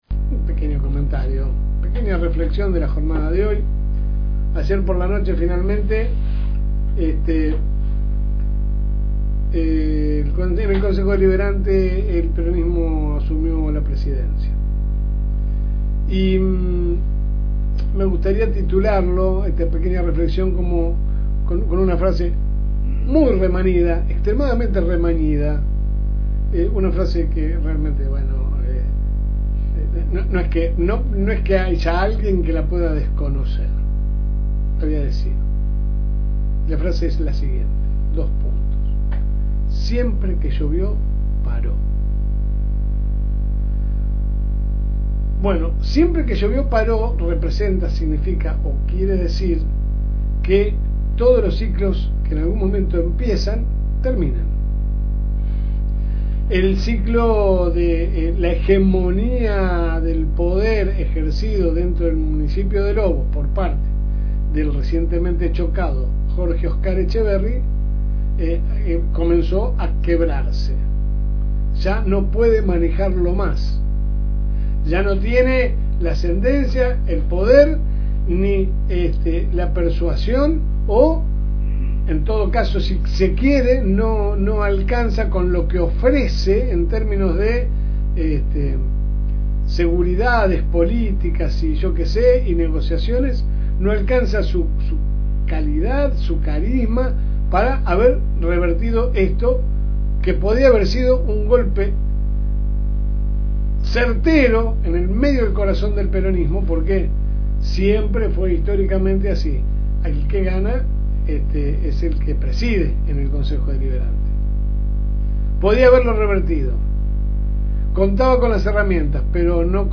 AUDIO – Pequeña reflexión matutina